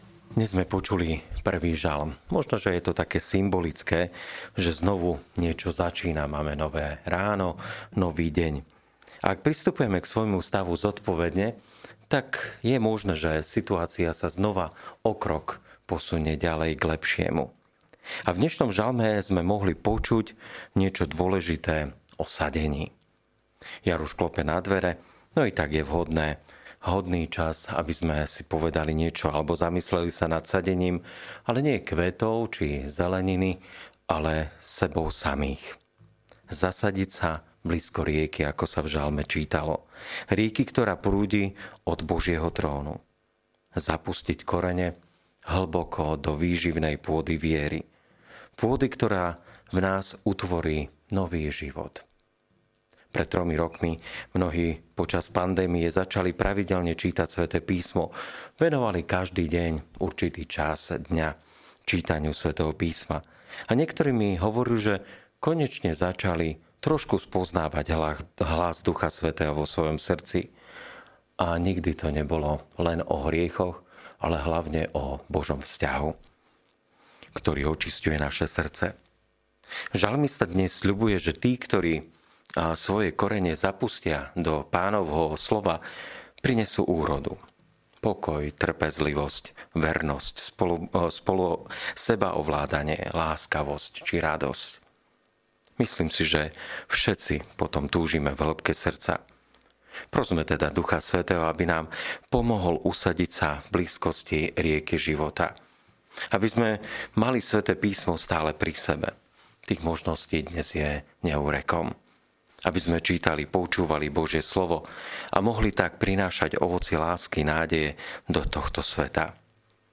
Podcasty Kázne ZASADIŤ SA BLÍZKO RIEKY